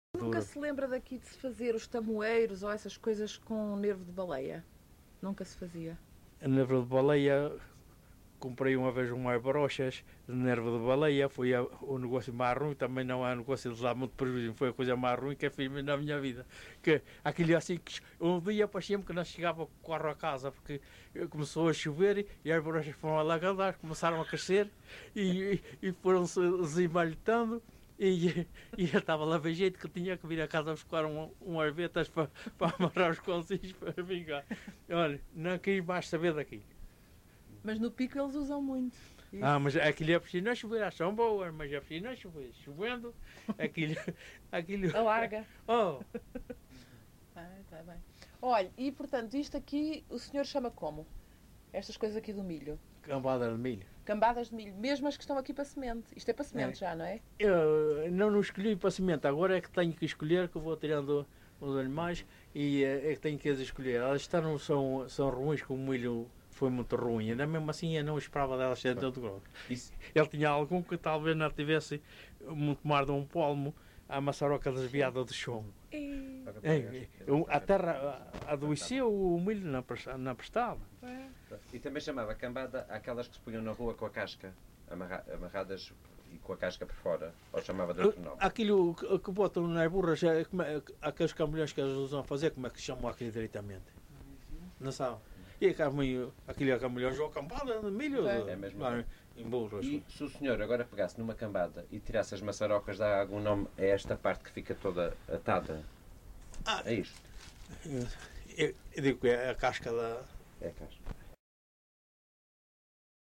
LocalidadeRibeira Seca (Calheta, Angra do Heroísmo)